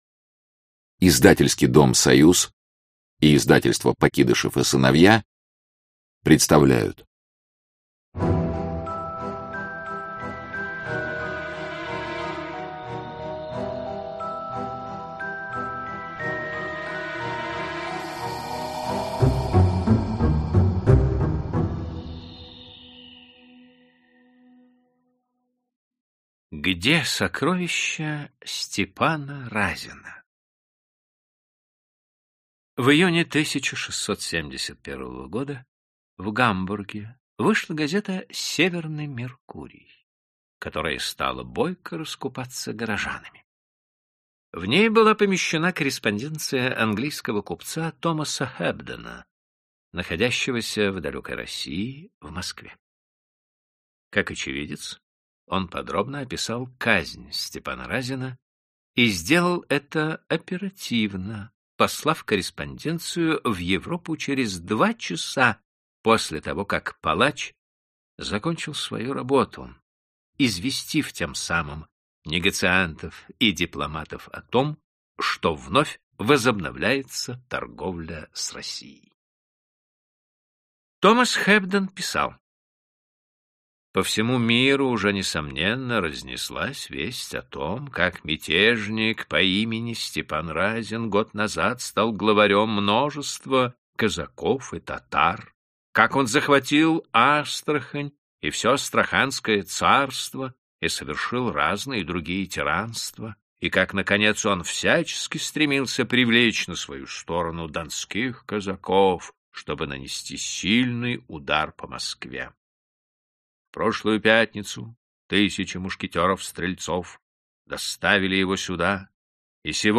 Аудиокнига Великие тайны нового времени | Библиотека аудиокниг